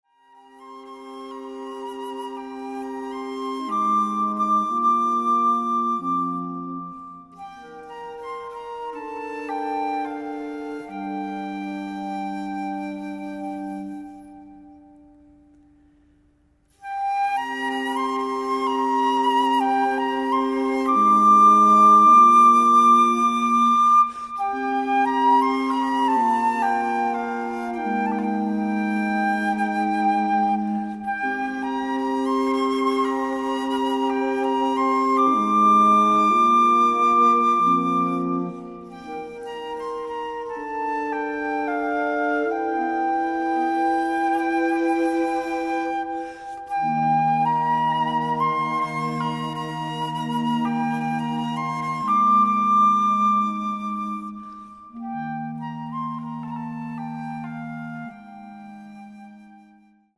flute
live eletronics
E' un progetto di stampo minimalista
all'interno della chiesa di Santa Chiara a Cagliari.